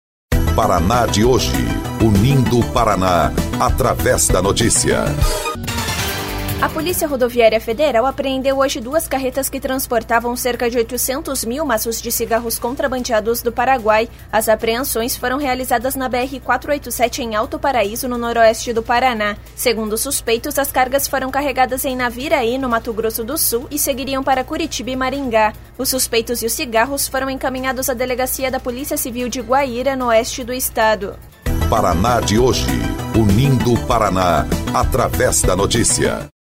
BOLETIM – PRF apreende cerca de 800 mil maços de cigarros contrabandeados